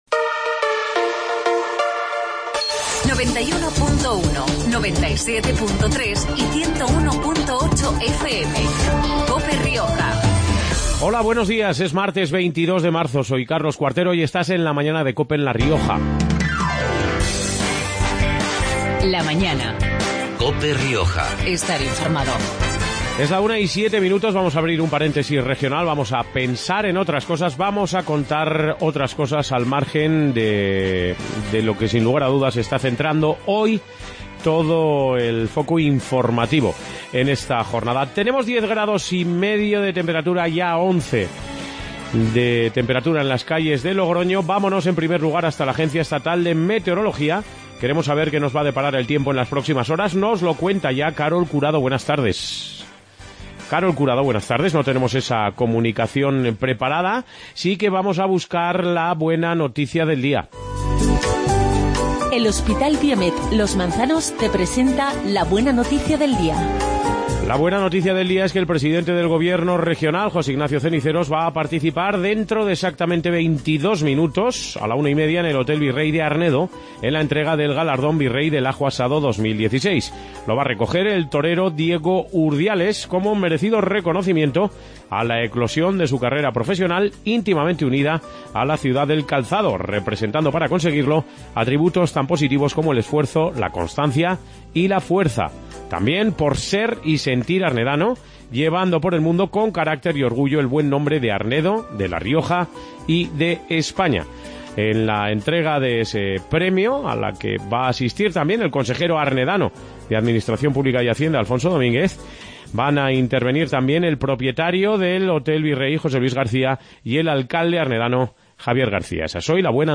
AUDIO: Programa regional de actualidad, entrevistas y entretenimiento. Hoy hablamos de Corpolinfa y del Centro Deportivo SPA Mercedes de Logroño.